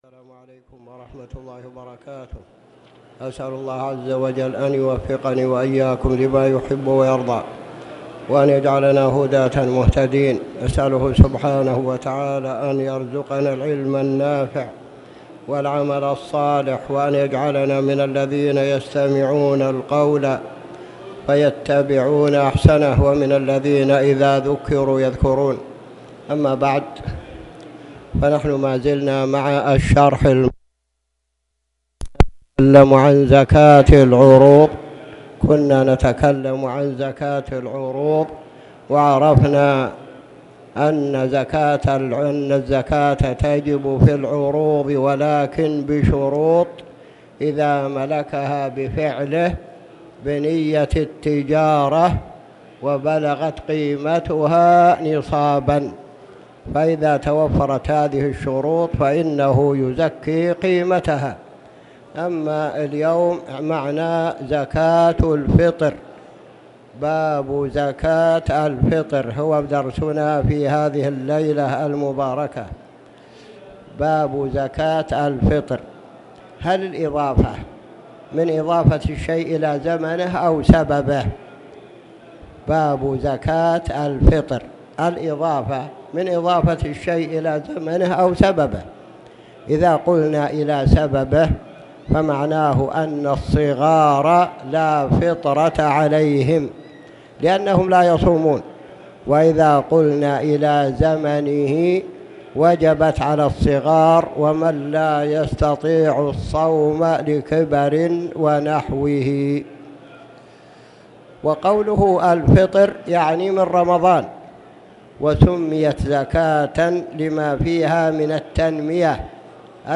تاريخ النشر ٢٦ جمادى الآخرة ١٤٣٨ هـ المكان: المسجد الحرام الشيخ